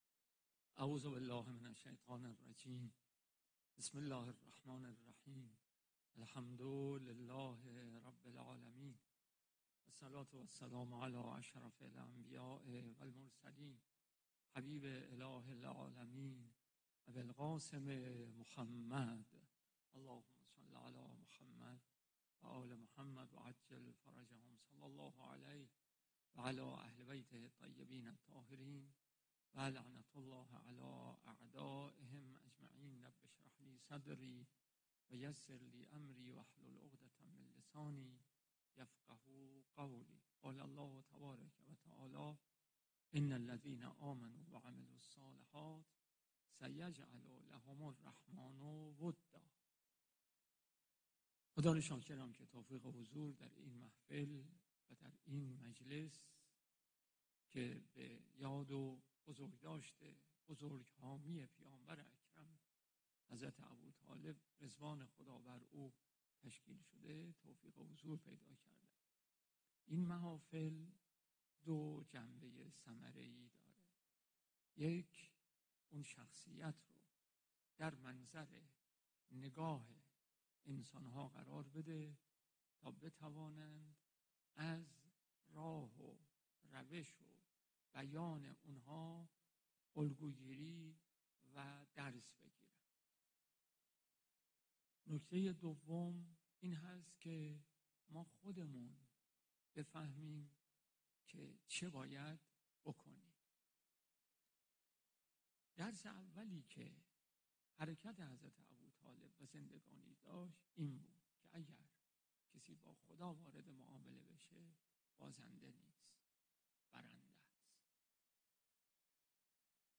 پیش از ظهر امروز در افتتاحیه همایش بین المللی حضرت ابوطالب